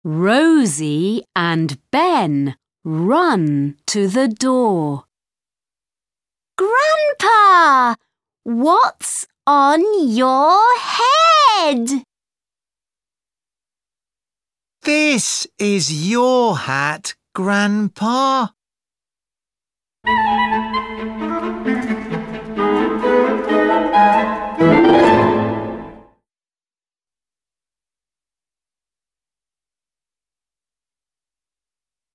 Track 5 Where's My Hat British English.mp3